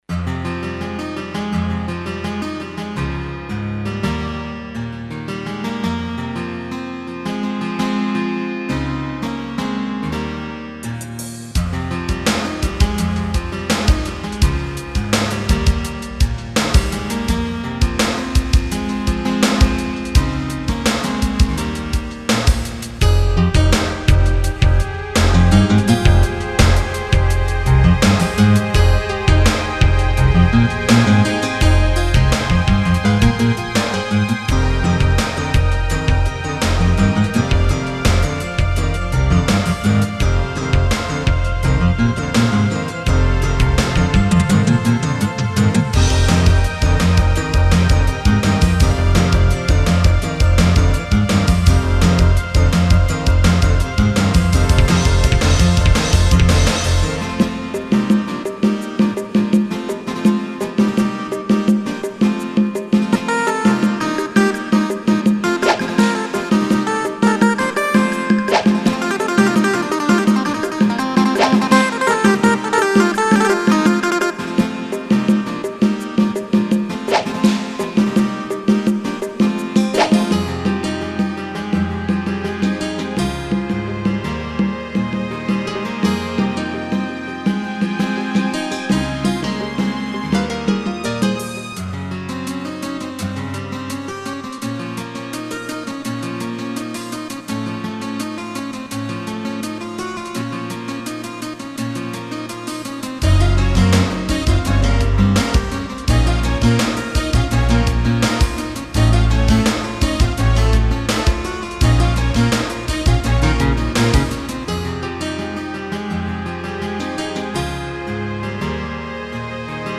MSC-02S;  MPC-02S Synth 1